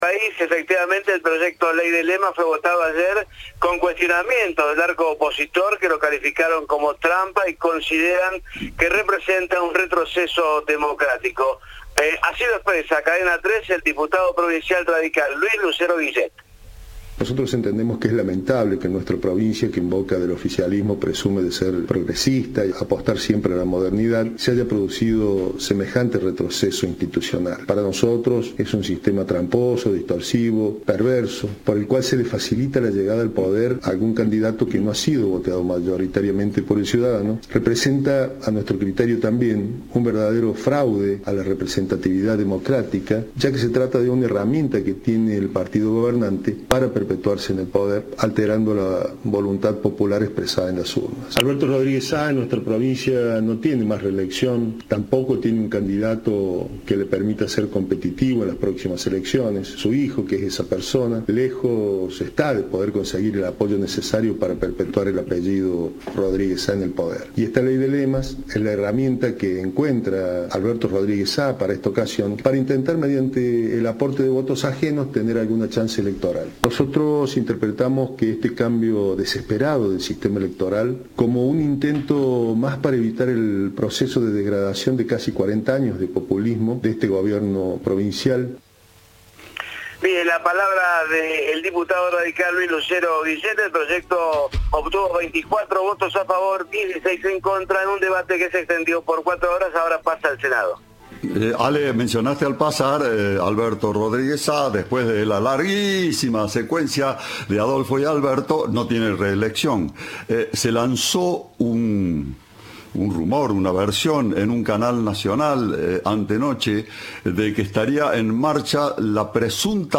En diálogo con Cadena 3, el diputado Luis Lucero Guillet dijo que "es lamentable que en una provincia en boca del oficialismo que dice ser progresista se haya producido semejante retroceso institucional".
Informe